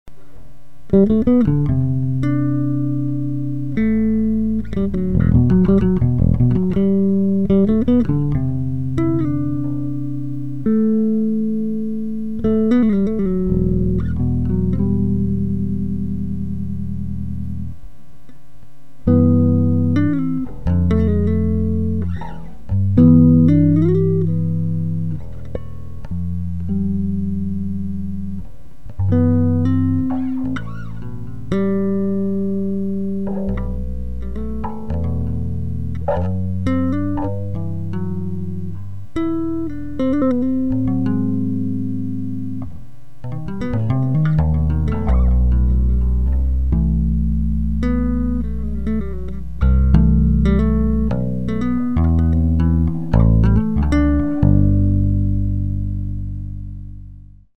This was recorded directly into the computer from the bass, with the bass onboard preamp OFF.
With the preamp OFF, there is no tone-shaping available (no passive tone controls): this is the bass' unprocessed tone.
Both pickups equally blended.